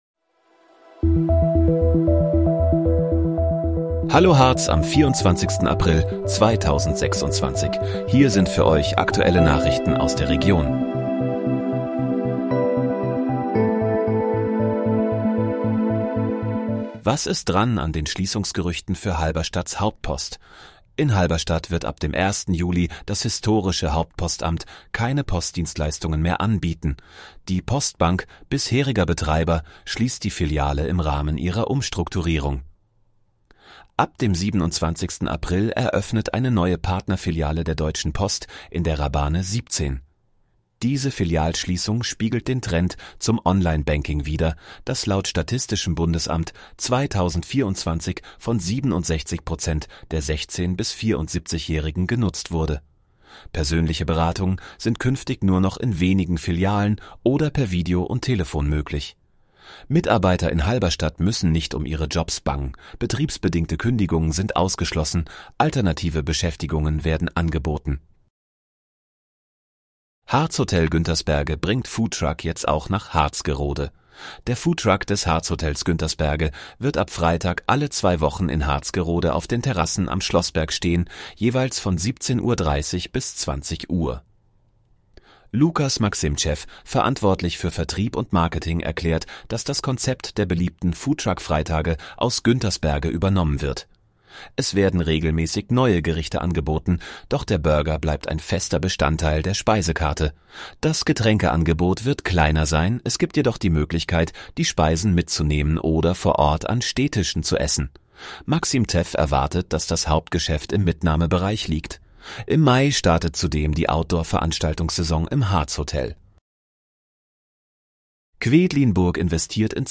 Hallo, Harz: Aktuelle Nachrichten vom 24.04.2026, erstellt mit KI-Unterstützung